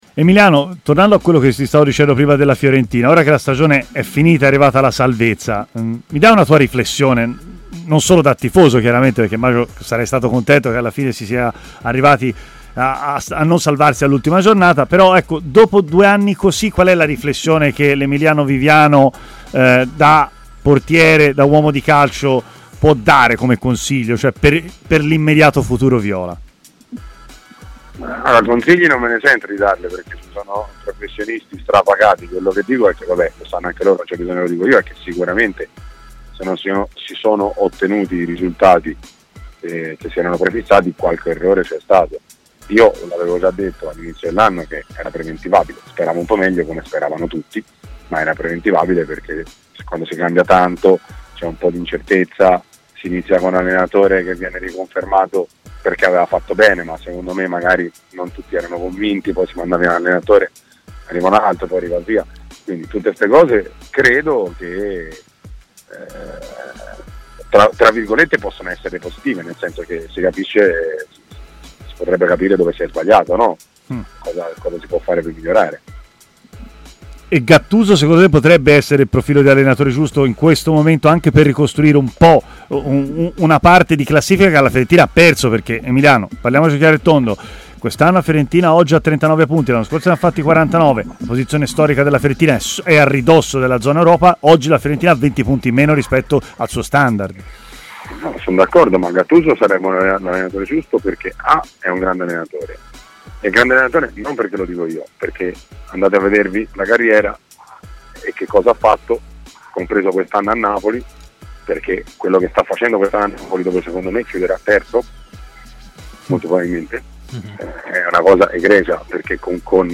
Emiliano Viviano, ex portiere viola e grande tifoso della Fiorentina, ora in Turchia al Karagumruk dove conta di rimanere ancora, ha parlato tra le tante cose anche del club gigliato a "Stadio Aperto" su TMW Radio: "Sicuramente non si sono ottenuti i risultati previsti, vuol dire che qualche errore c'è stato.